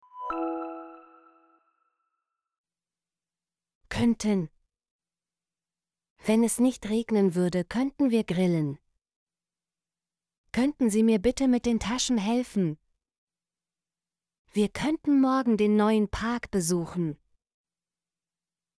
könnten - Example Sentences & Pronunciation, German Frequency List